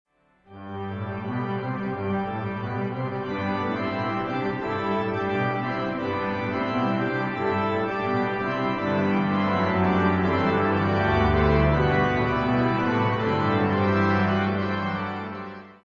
recorded  on the Christchurch Town Hall